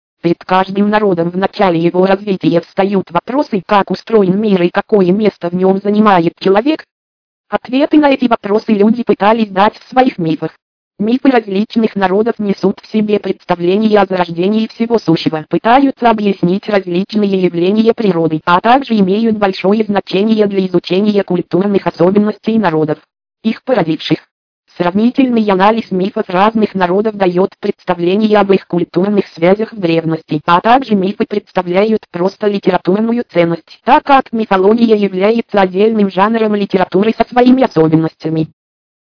L&H tts 3000 Russian (Lernout & Hauspie) - это русскоязычный синтезатор стандарта SAPI4.
Данный синтезатор имеет определённую картавость в произношении, однако к его плюсам можно отнести довольно приличное произношение не только русского, но и английского текста, которое позволяет использовать его и для чтения англоязычных документов.